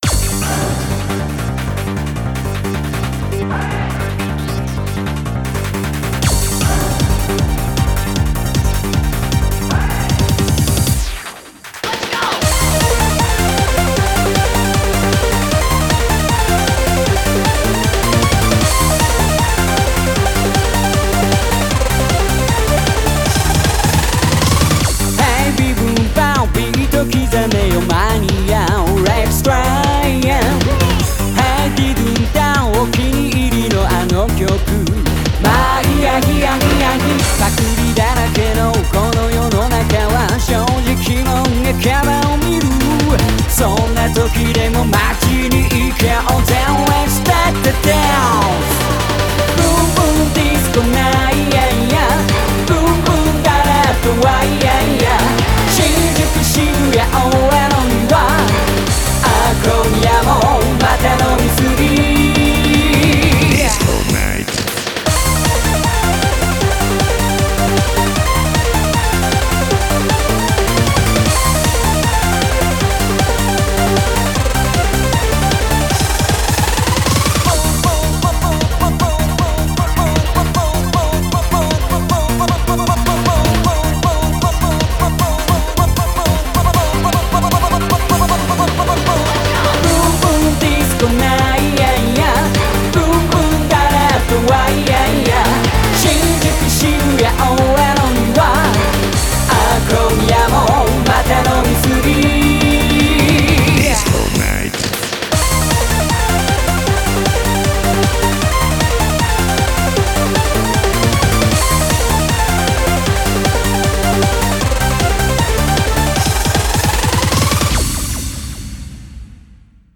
BPM155